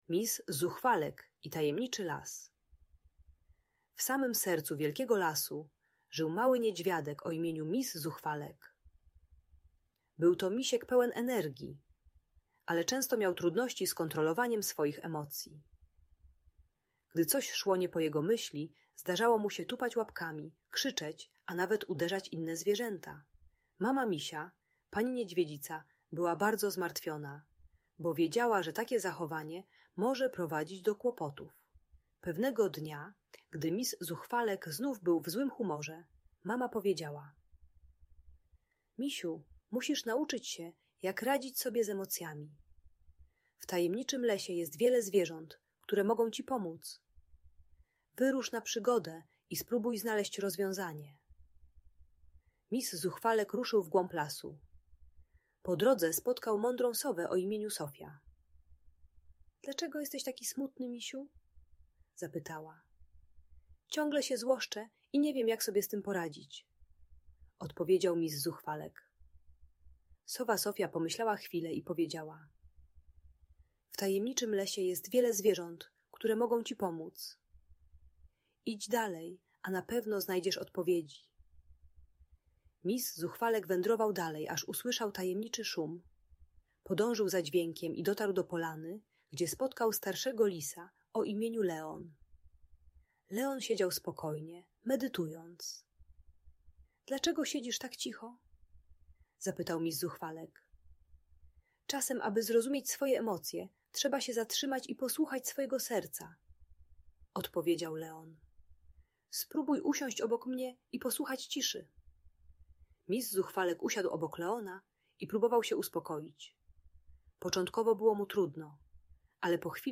Przygoda Misia Zuchwalka - Bunt i wybuchy złości | Audiobajka
Mis Zuchwalek uczy techniki głębokiego oddechu i liczenia do dziesięciu, gdy czuje narastającą złość. Audiobajka o radzeniu sobie z agresją i kontrolowaniu emocji u małych dzieci.